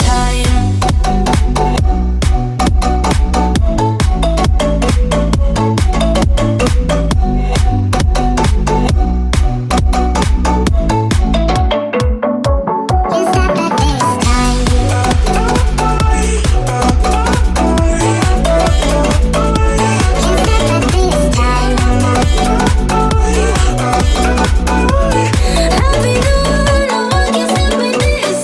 house
Genere: house, deep house, remix